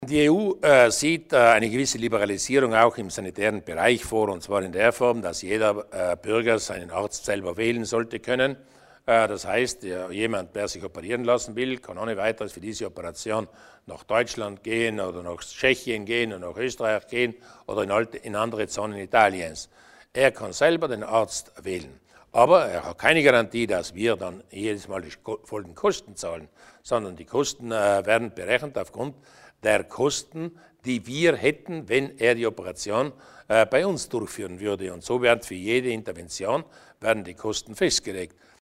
Landeshauptmann Durnwalder über die Details der EU-Patientenfreiheit